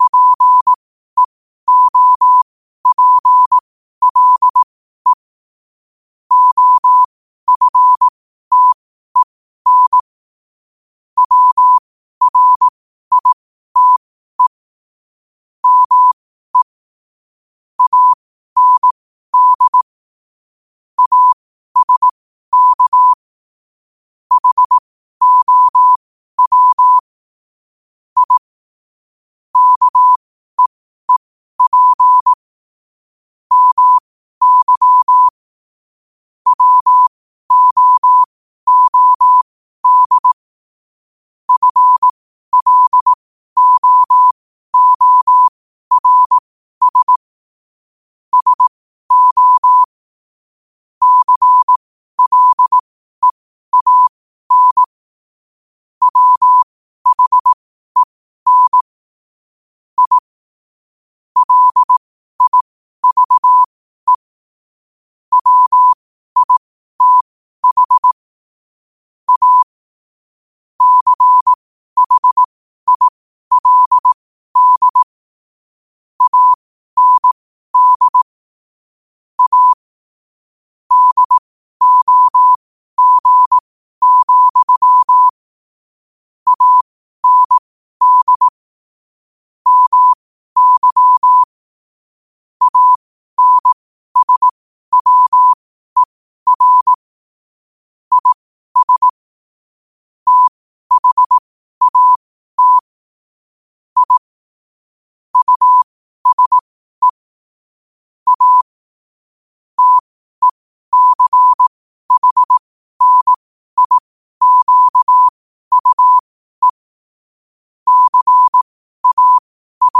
New quotes every day in morse code at 12 Words per minute.